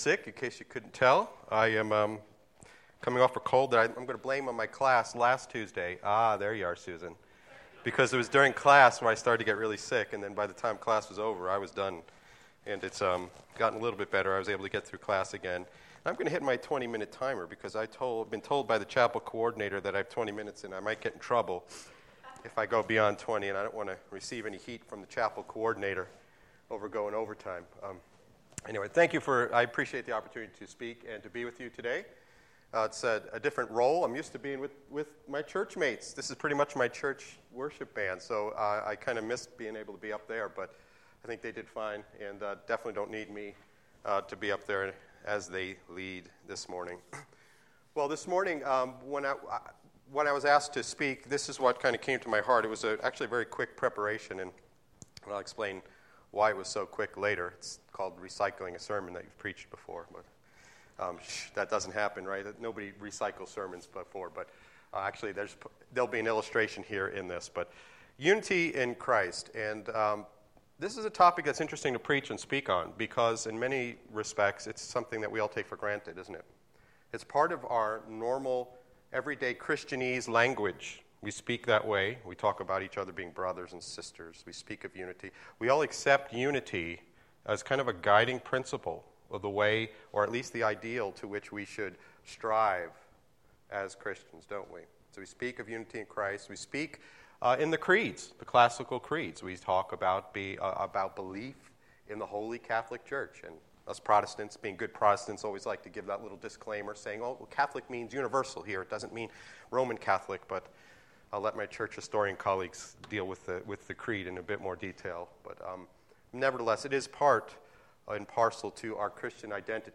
The message was recorded on Wednesday February 25, 2015 during our Seminary Chapel.